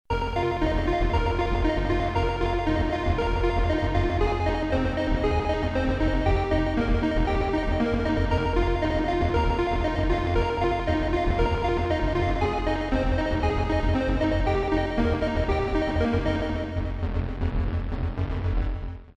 16 Bit Digital Synthesizer
demo 8 bits:-